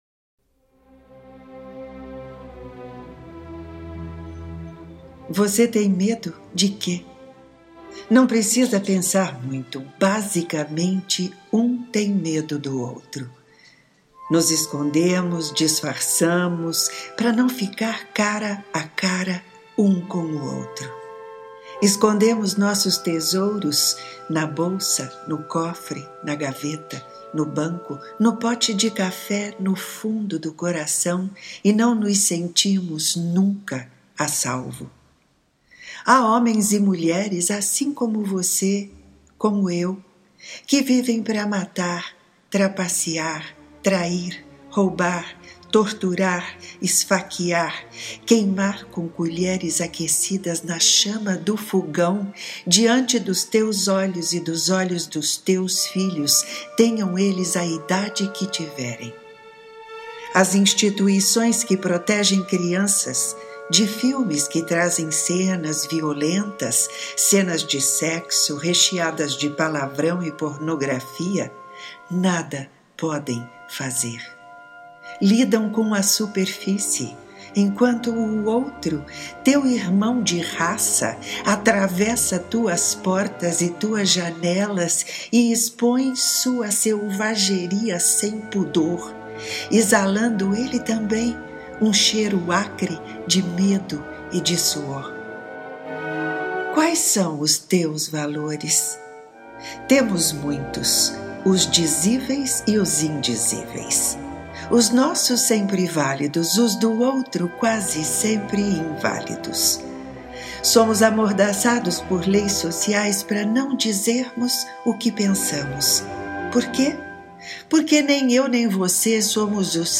Ouça este texto na voz e sonorizado pela autora